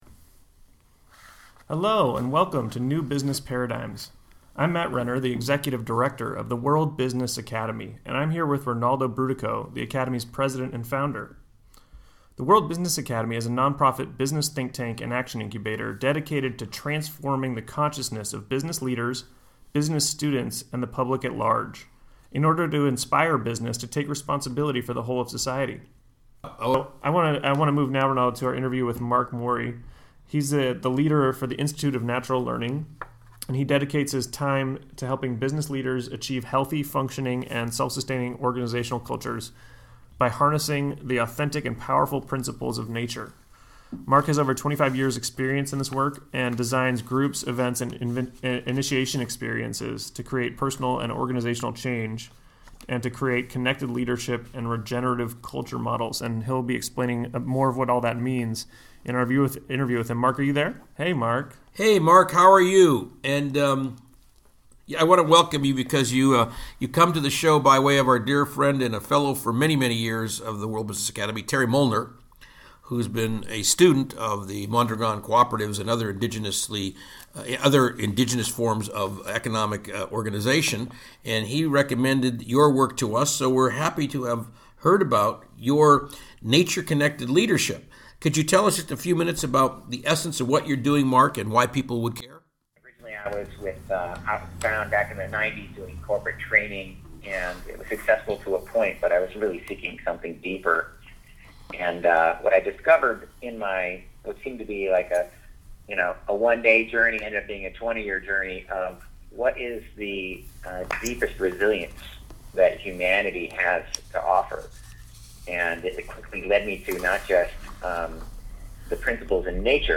World Business Academy Radio Interview